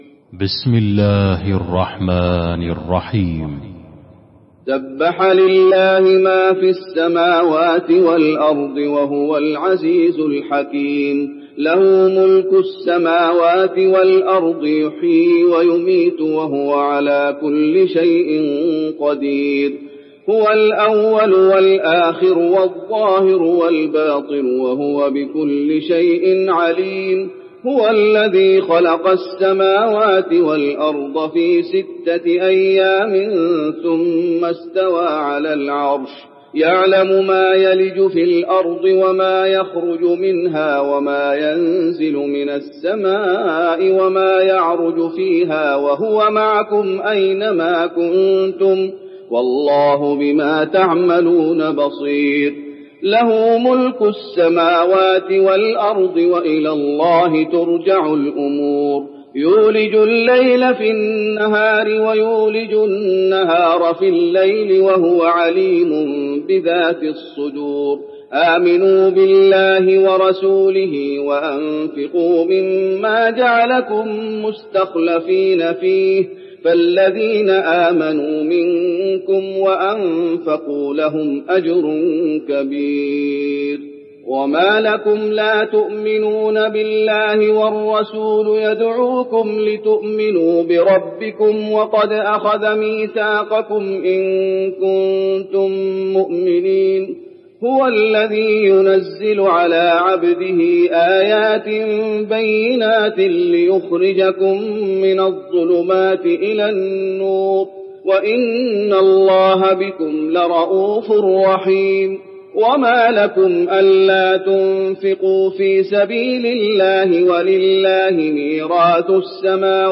المكان: المسجد النبوي الحديد